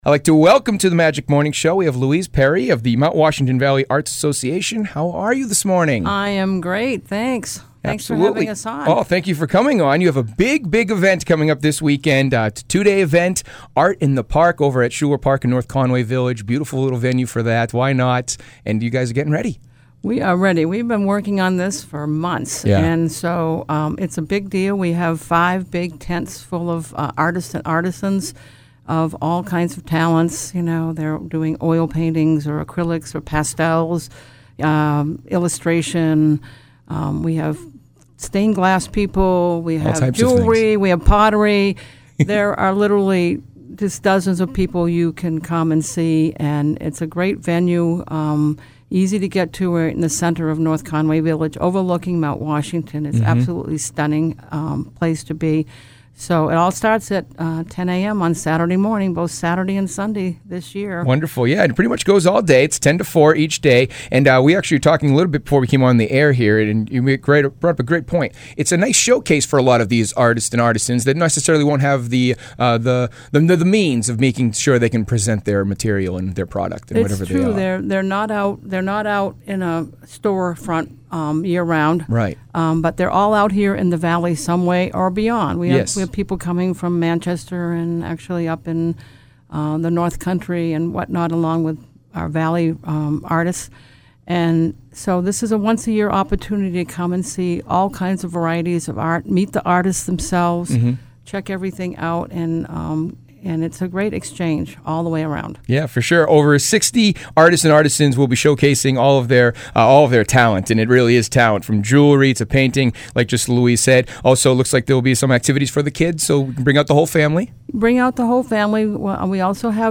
Magic Morning Show